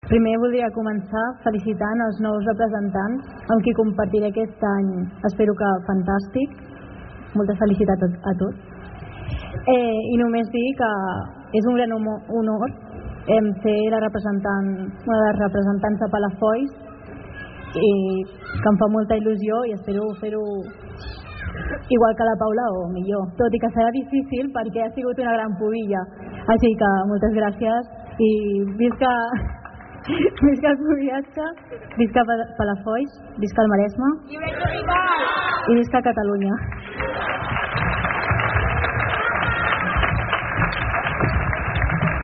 A la tarda es va seguir amb una cercavila, on hi participaven un centenar d’hereus i pubilles d’arreu de Catalunya, i que va acabar a la plaça de Poppi, on es va fer la proclamació dels nous hereu i pubilla.